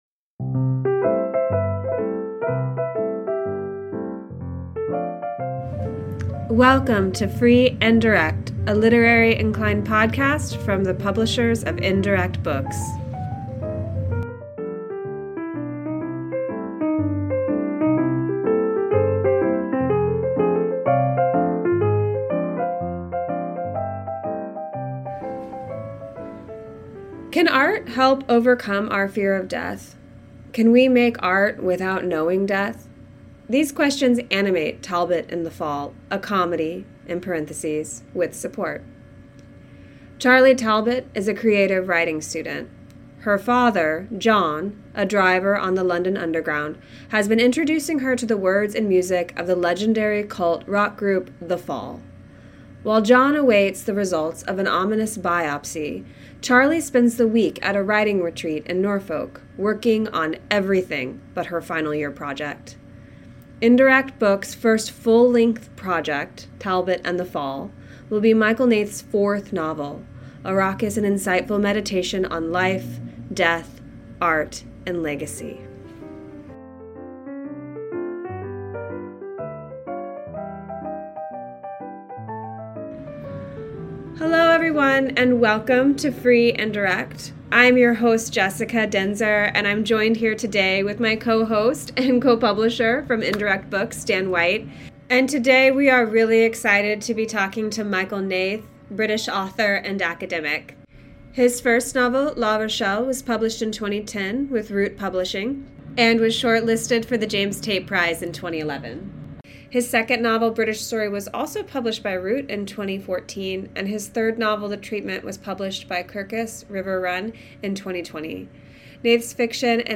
I hope you enjoy this conversation, and in the meantime—stay critical.